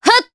Aselica-Vox_Attack1_jp.wav